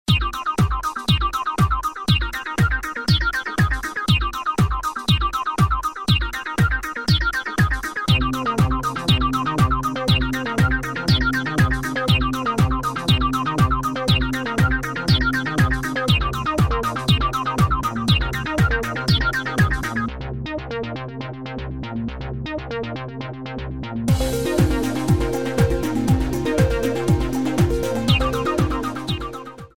10 Music tracks in various dance styles.
Warm-up, Cool Down, Modern, Jazz, Hip Hop